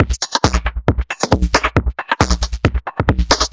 Index of /musicradar/uk-garage-samples/136bpm Lines n Loops/Beats
GA_BeatFilterB136-04.wav